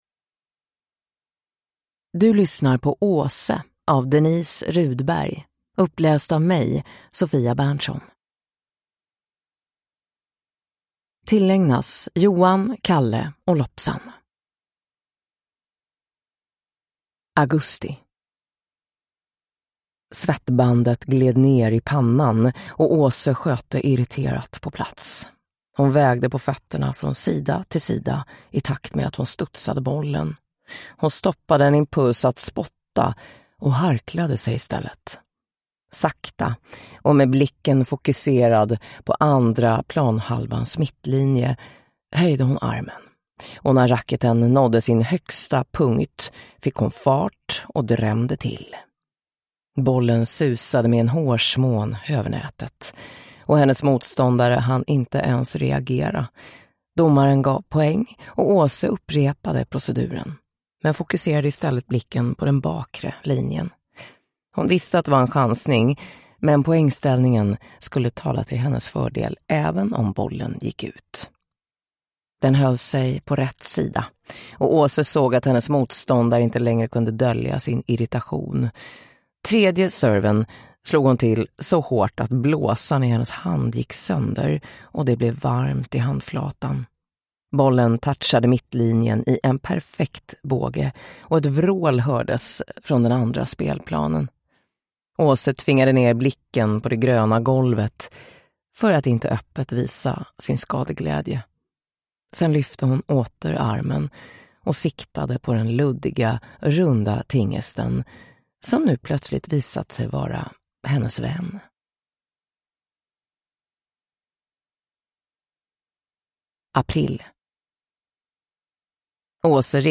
Åse – Ljudbok